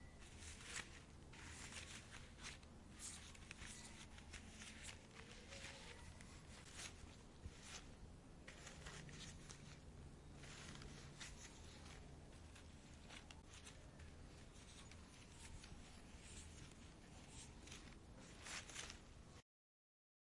Звук подправления цветов в букете